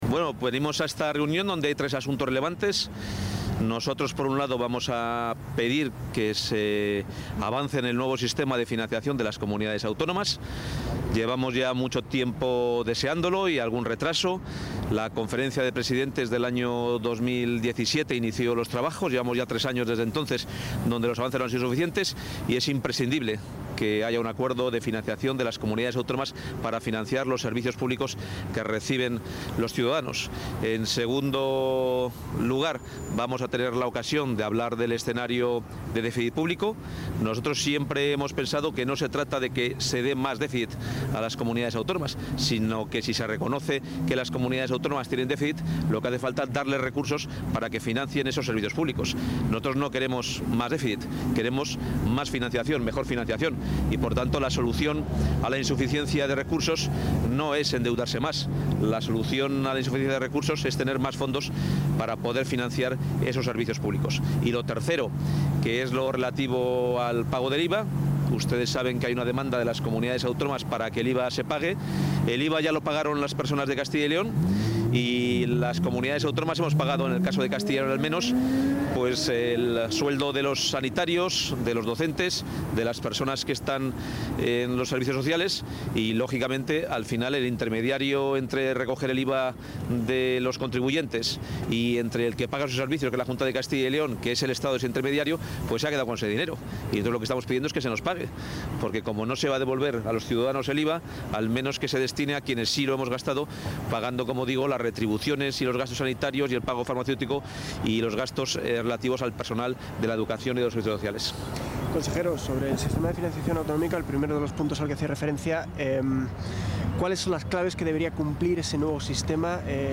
Declaraciones del consejero de Economía y Hacienda antes del Consejo de Política Fiscal y Financiera
Audio consejero.